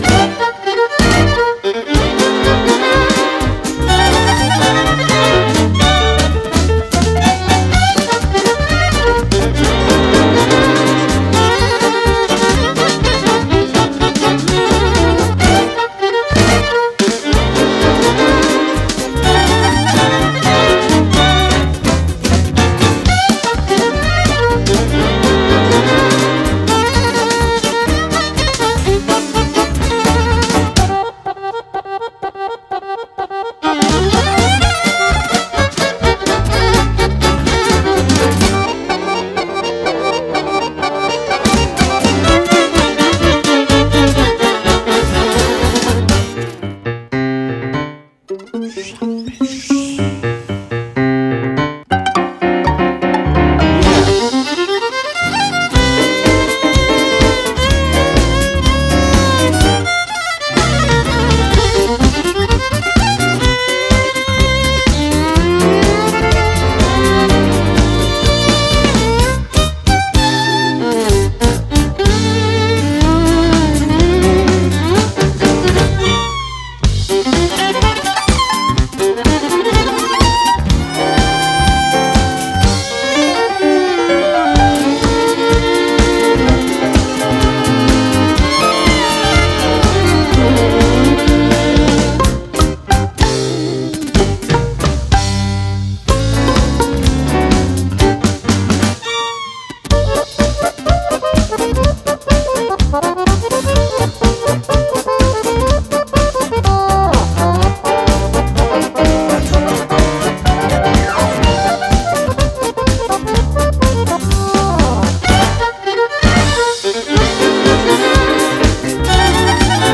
serbian roma | uk